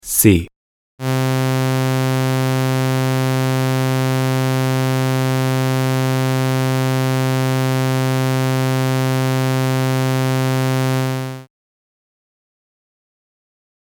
trombone tunings
scale
(note: the C# in this scale ended up not getting used in the piece, ignore it)